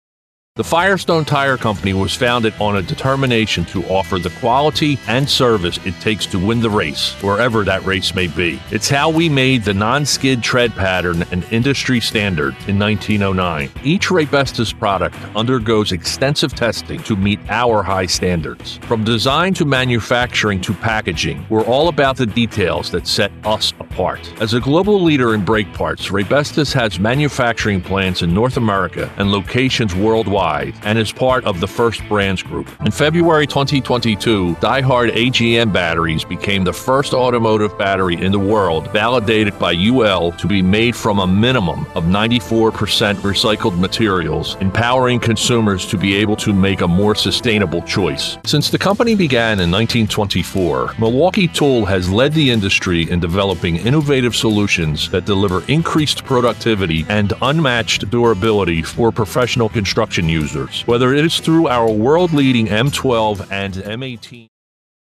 Never AI, always authentic.
Professional-grade equipment and acoustic treatment deliver broadcast-ready audio that rivals major market studios in New York and Los Angeles.
Auto & Parts Demo